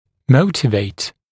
[‘məutɪveɪt][‘моутивэйт]мотивировать, побуждать